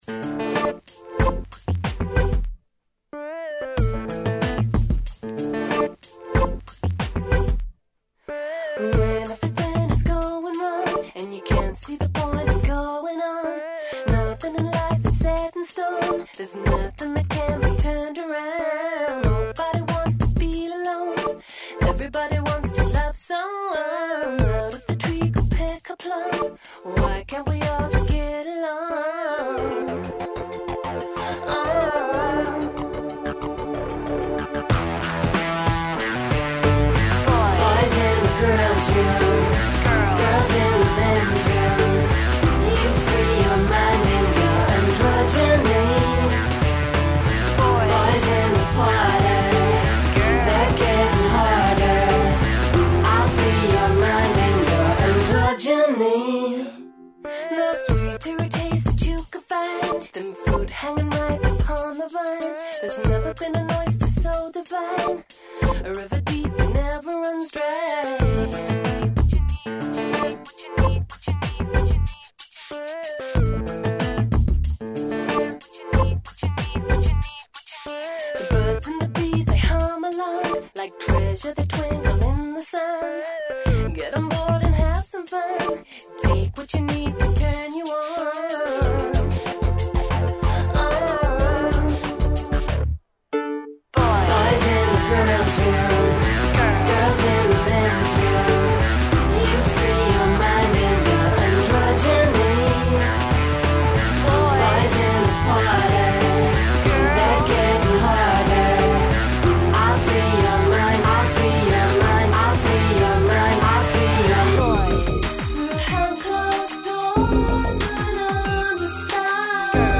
Графити и поруке деценије, цртице из првих корака живота у 21. веку које не треба заборавити... У студију